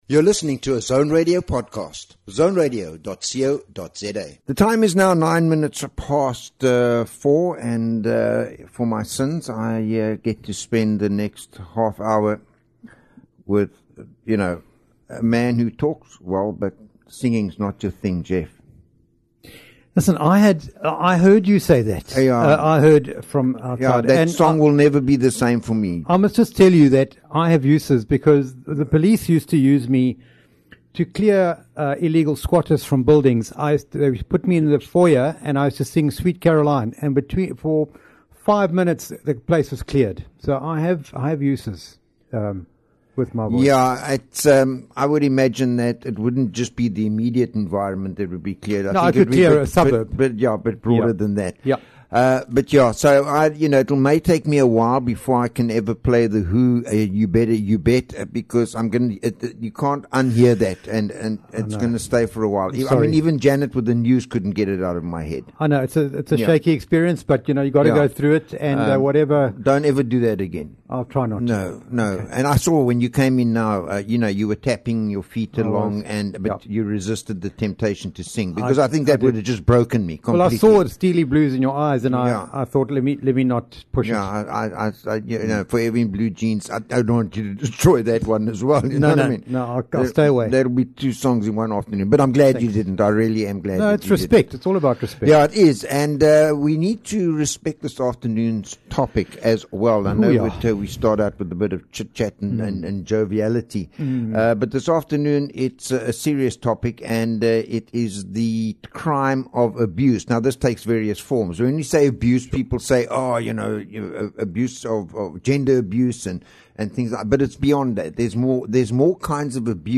is a bi-weekly radio show exploring the rich tapestry of human experiences.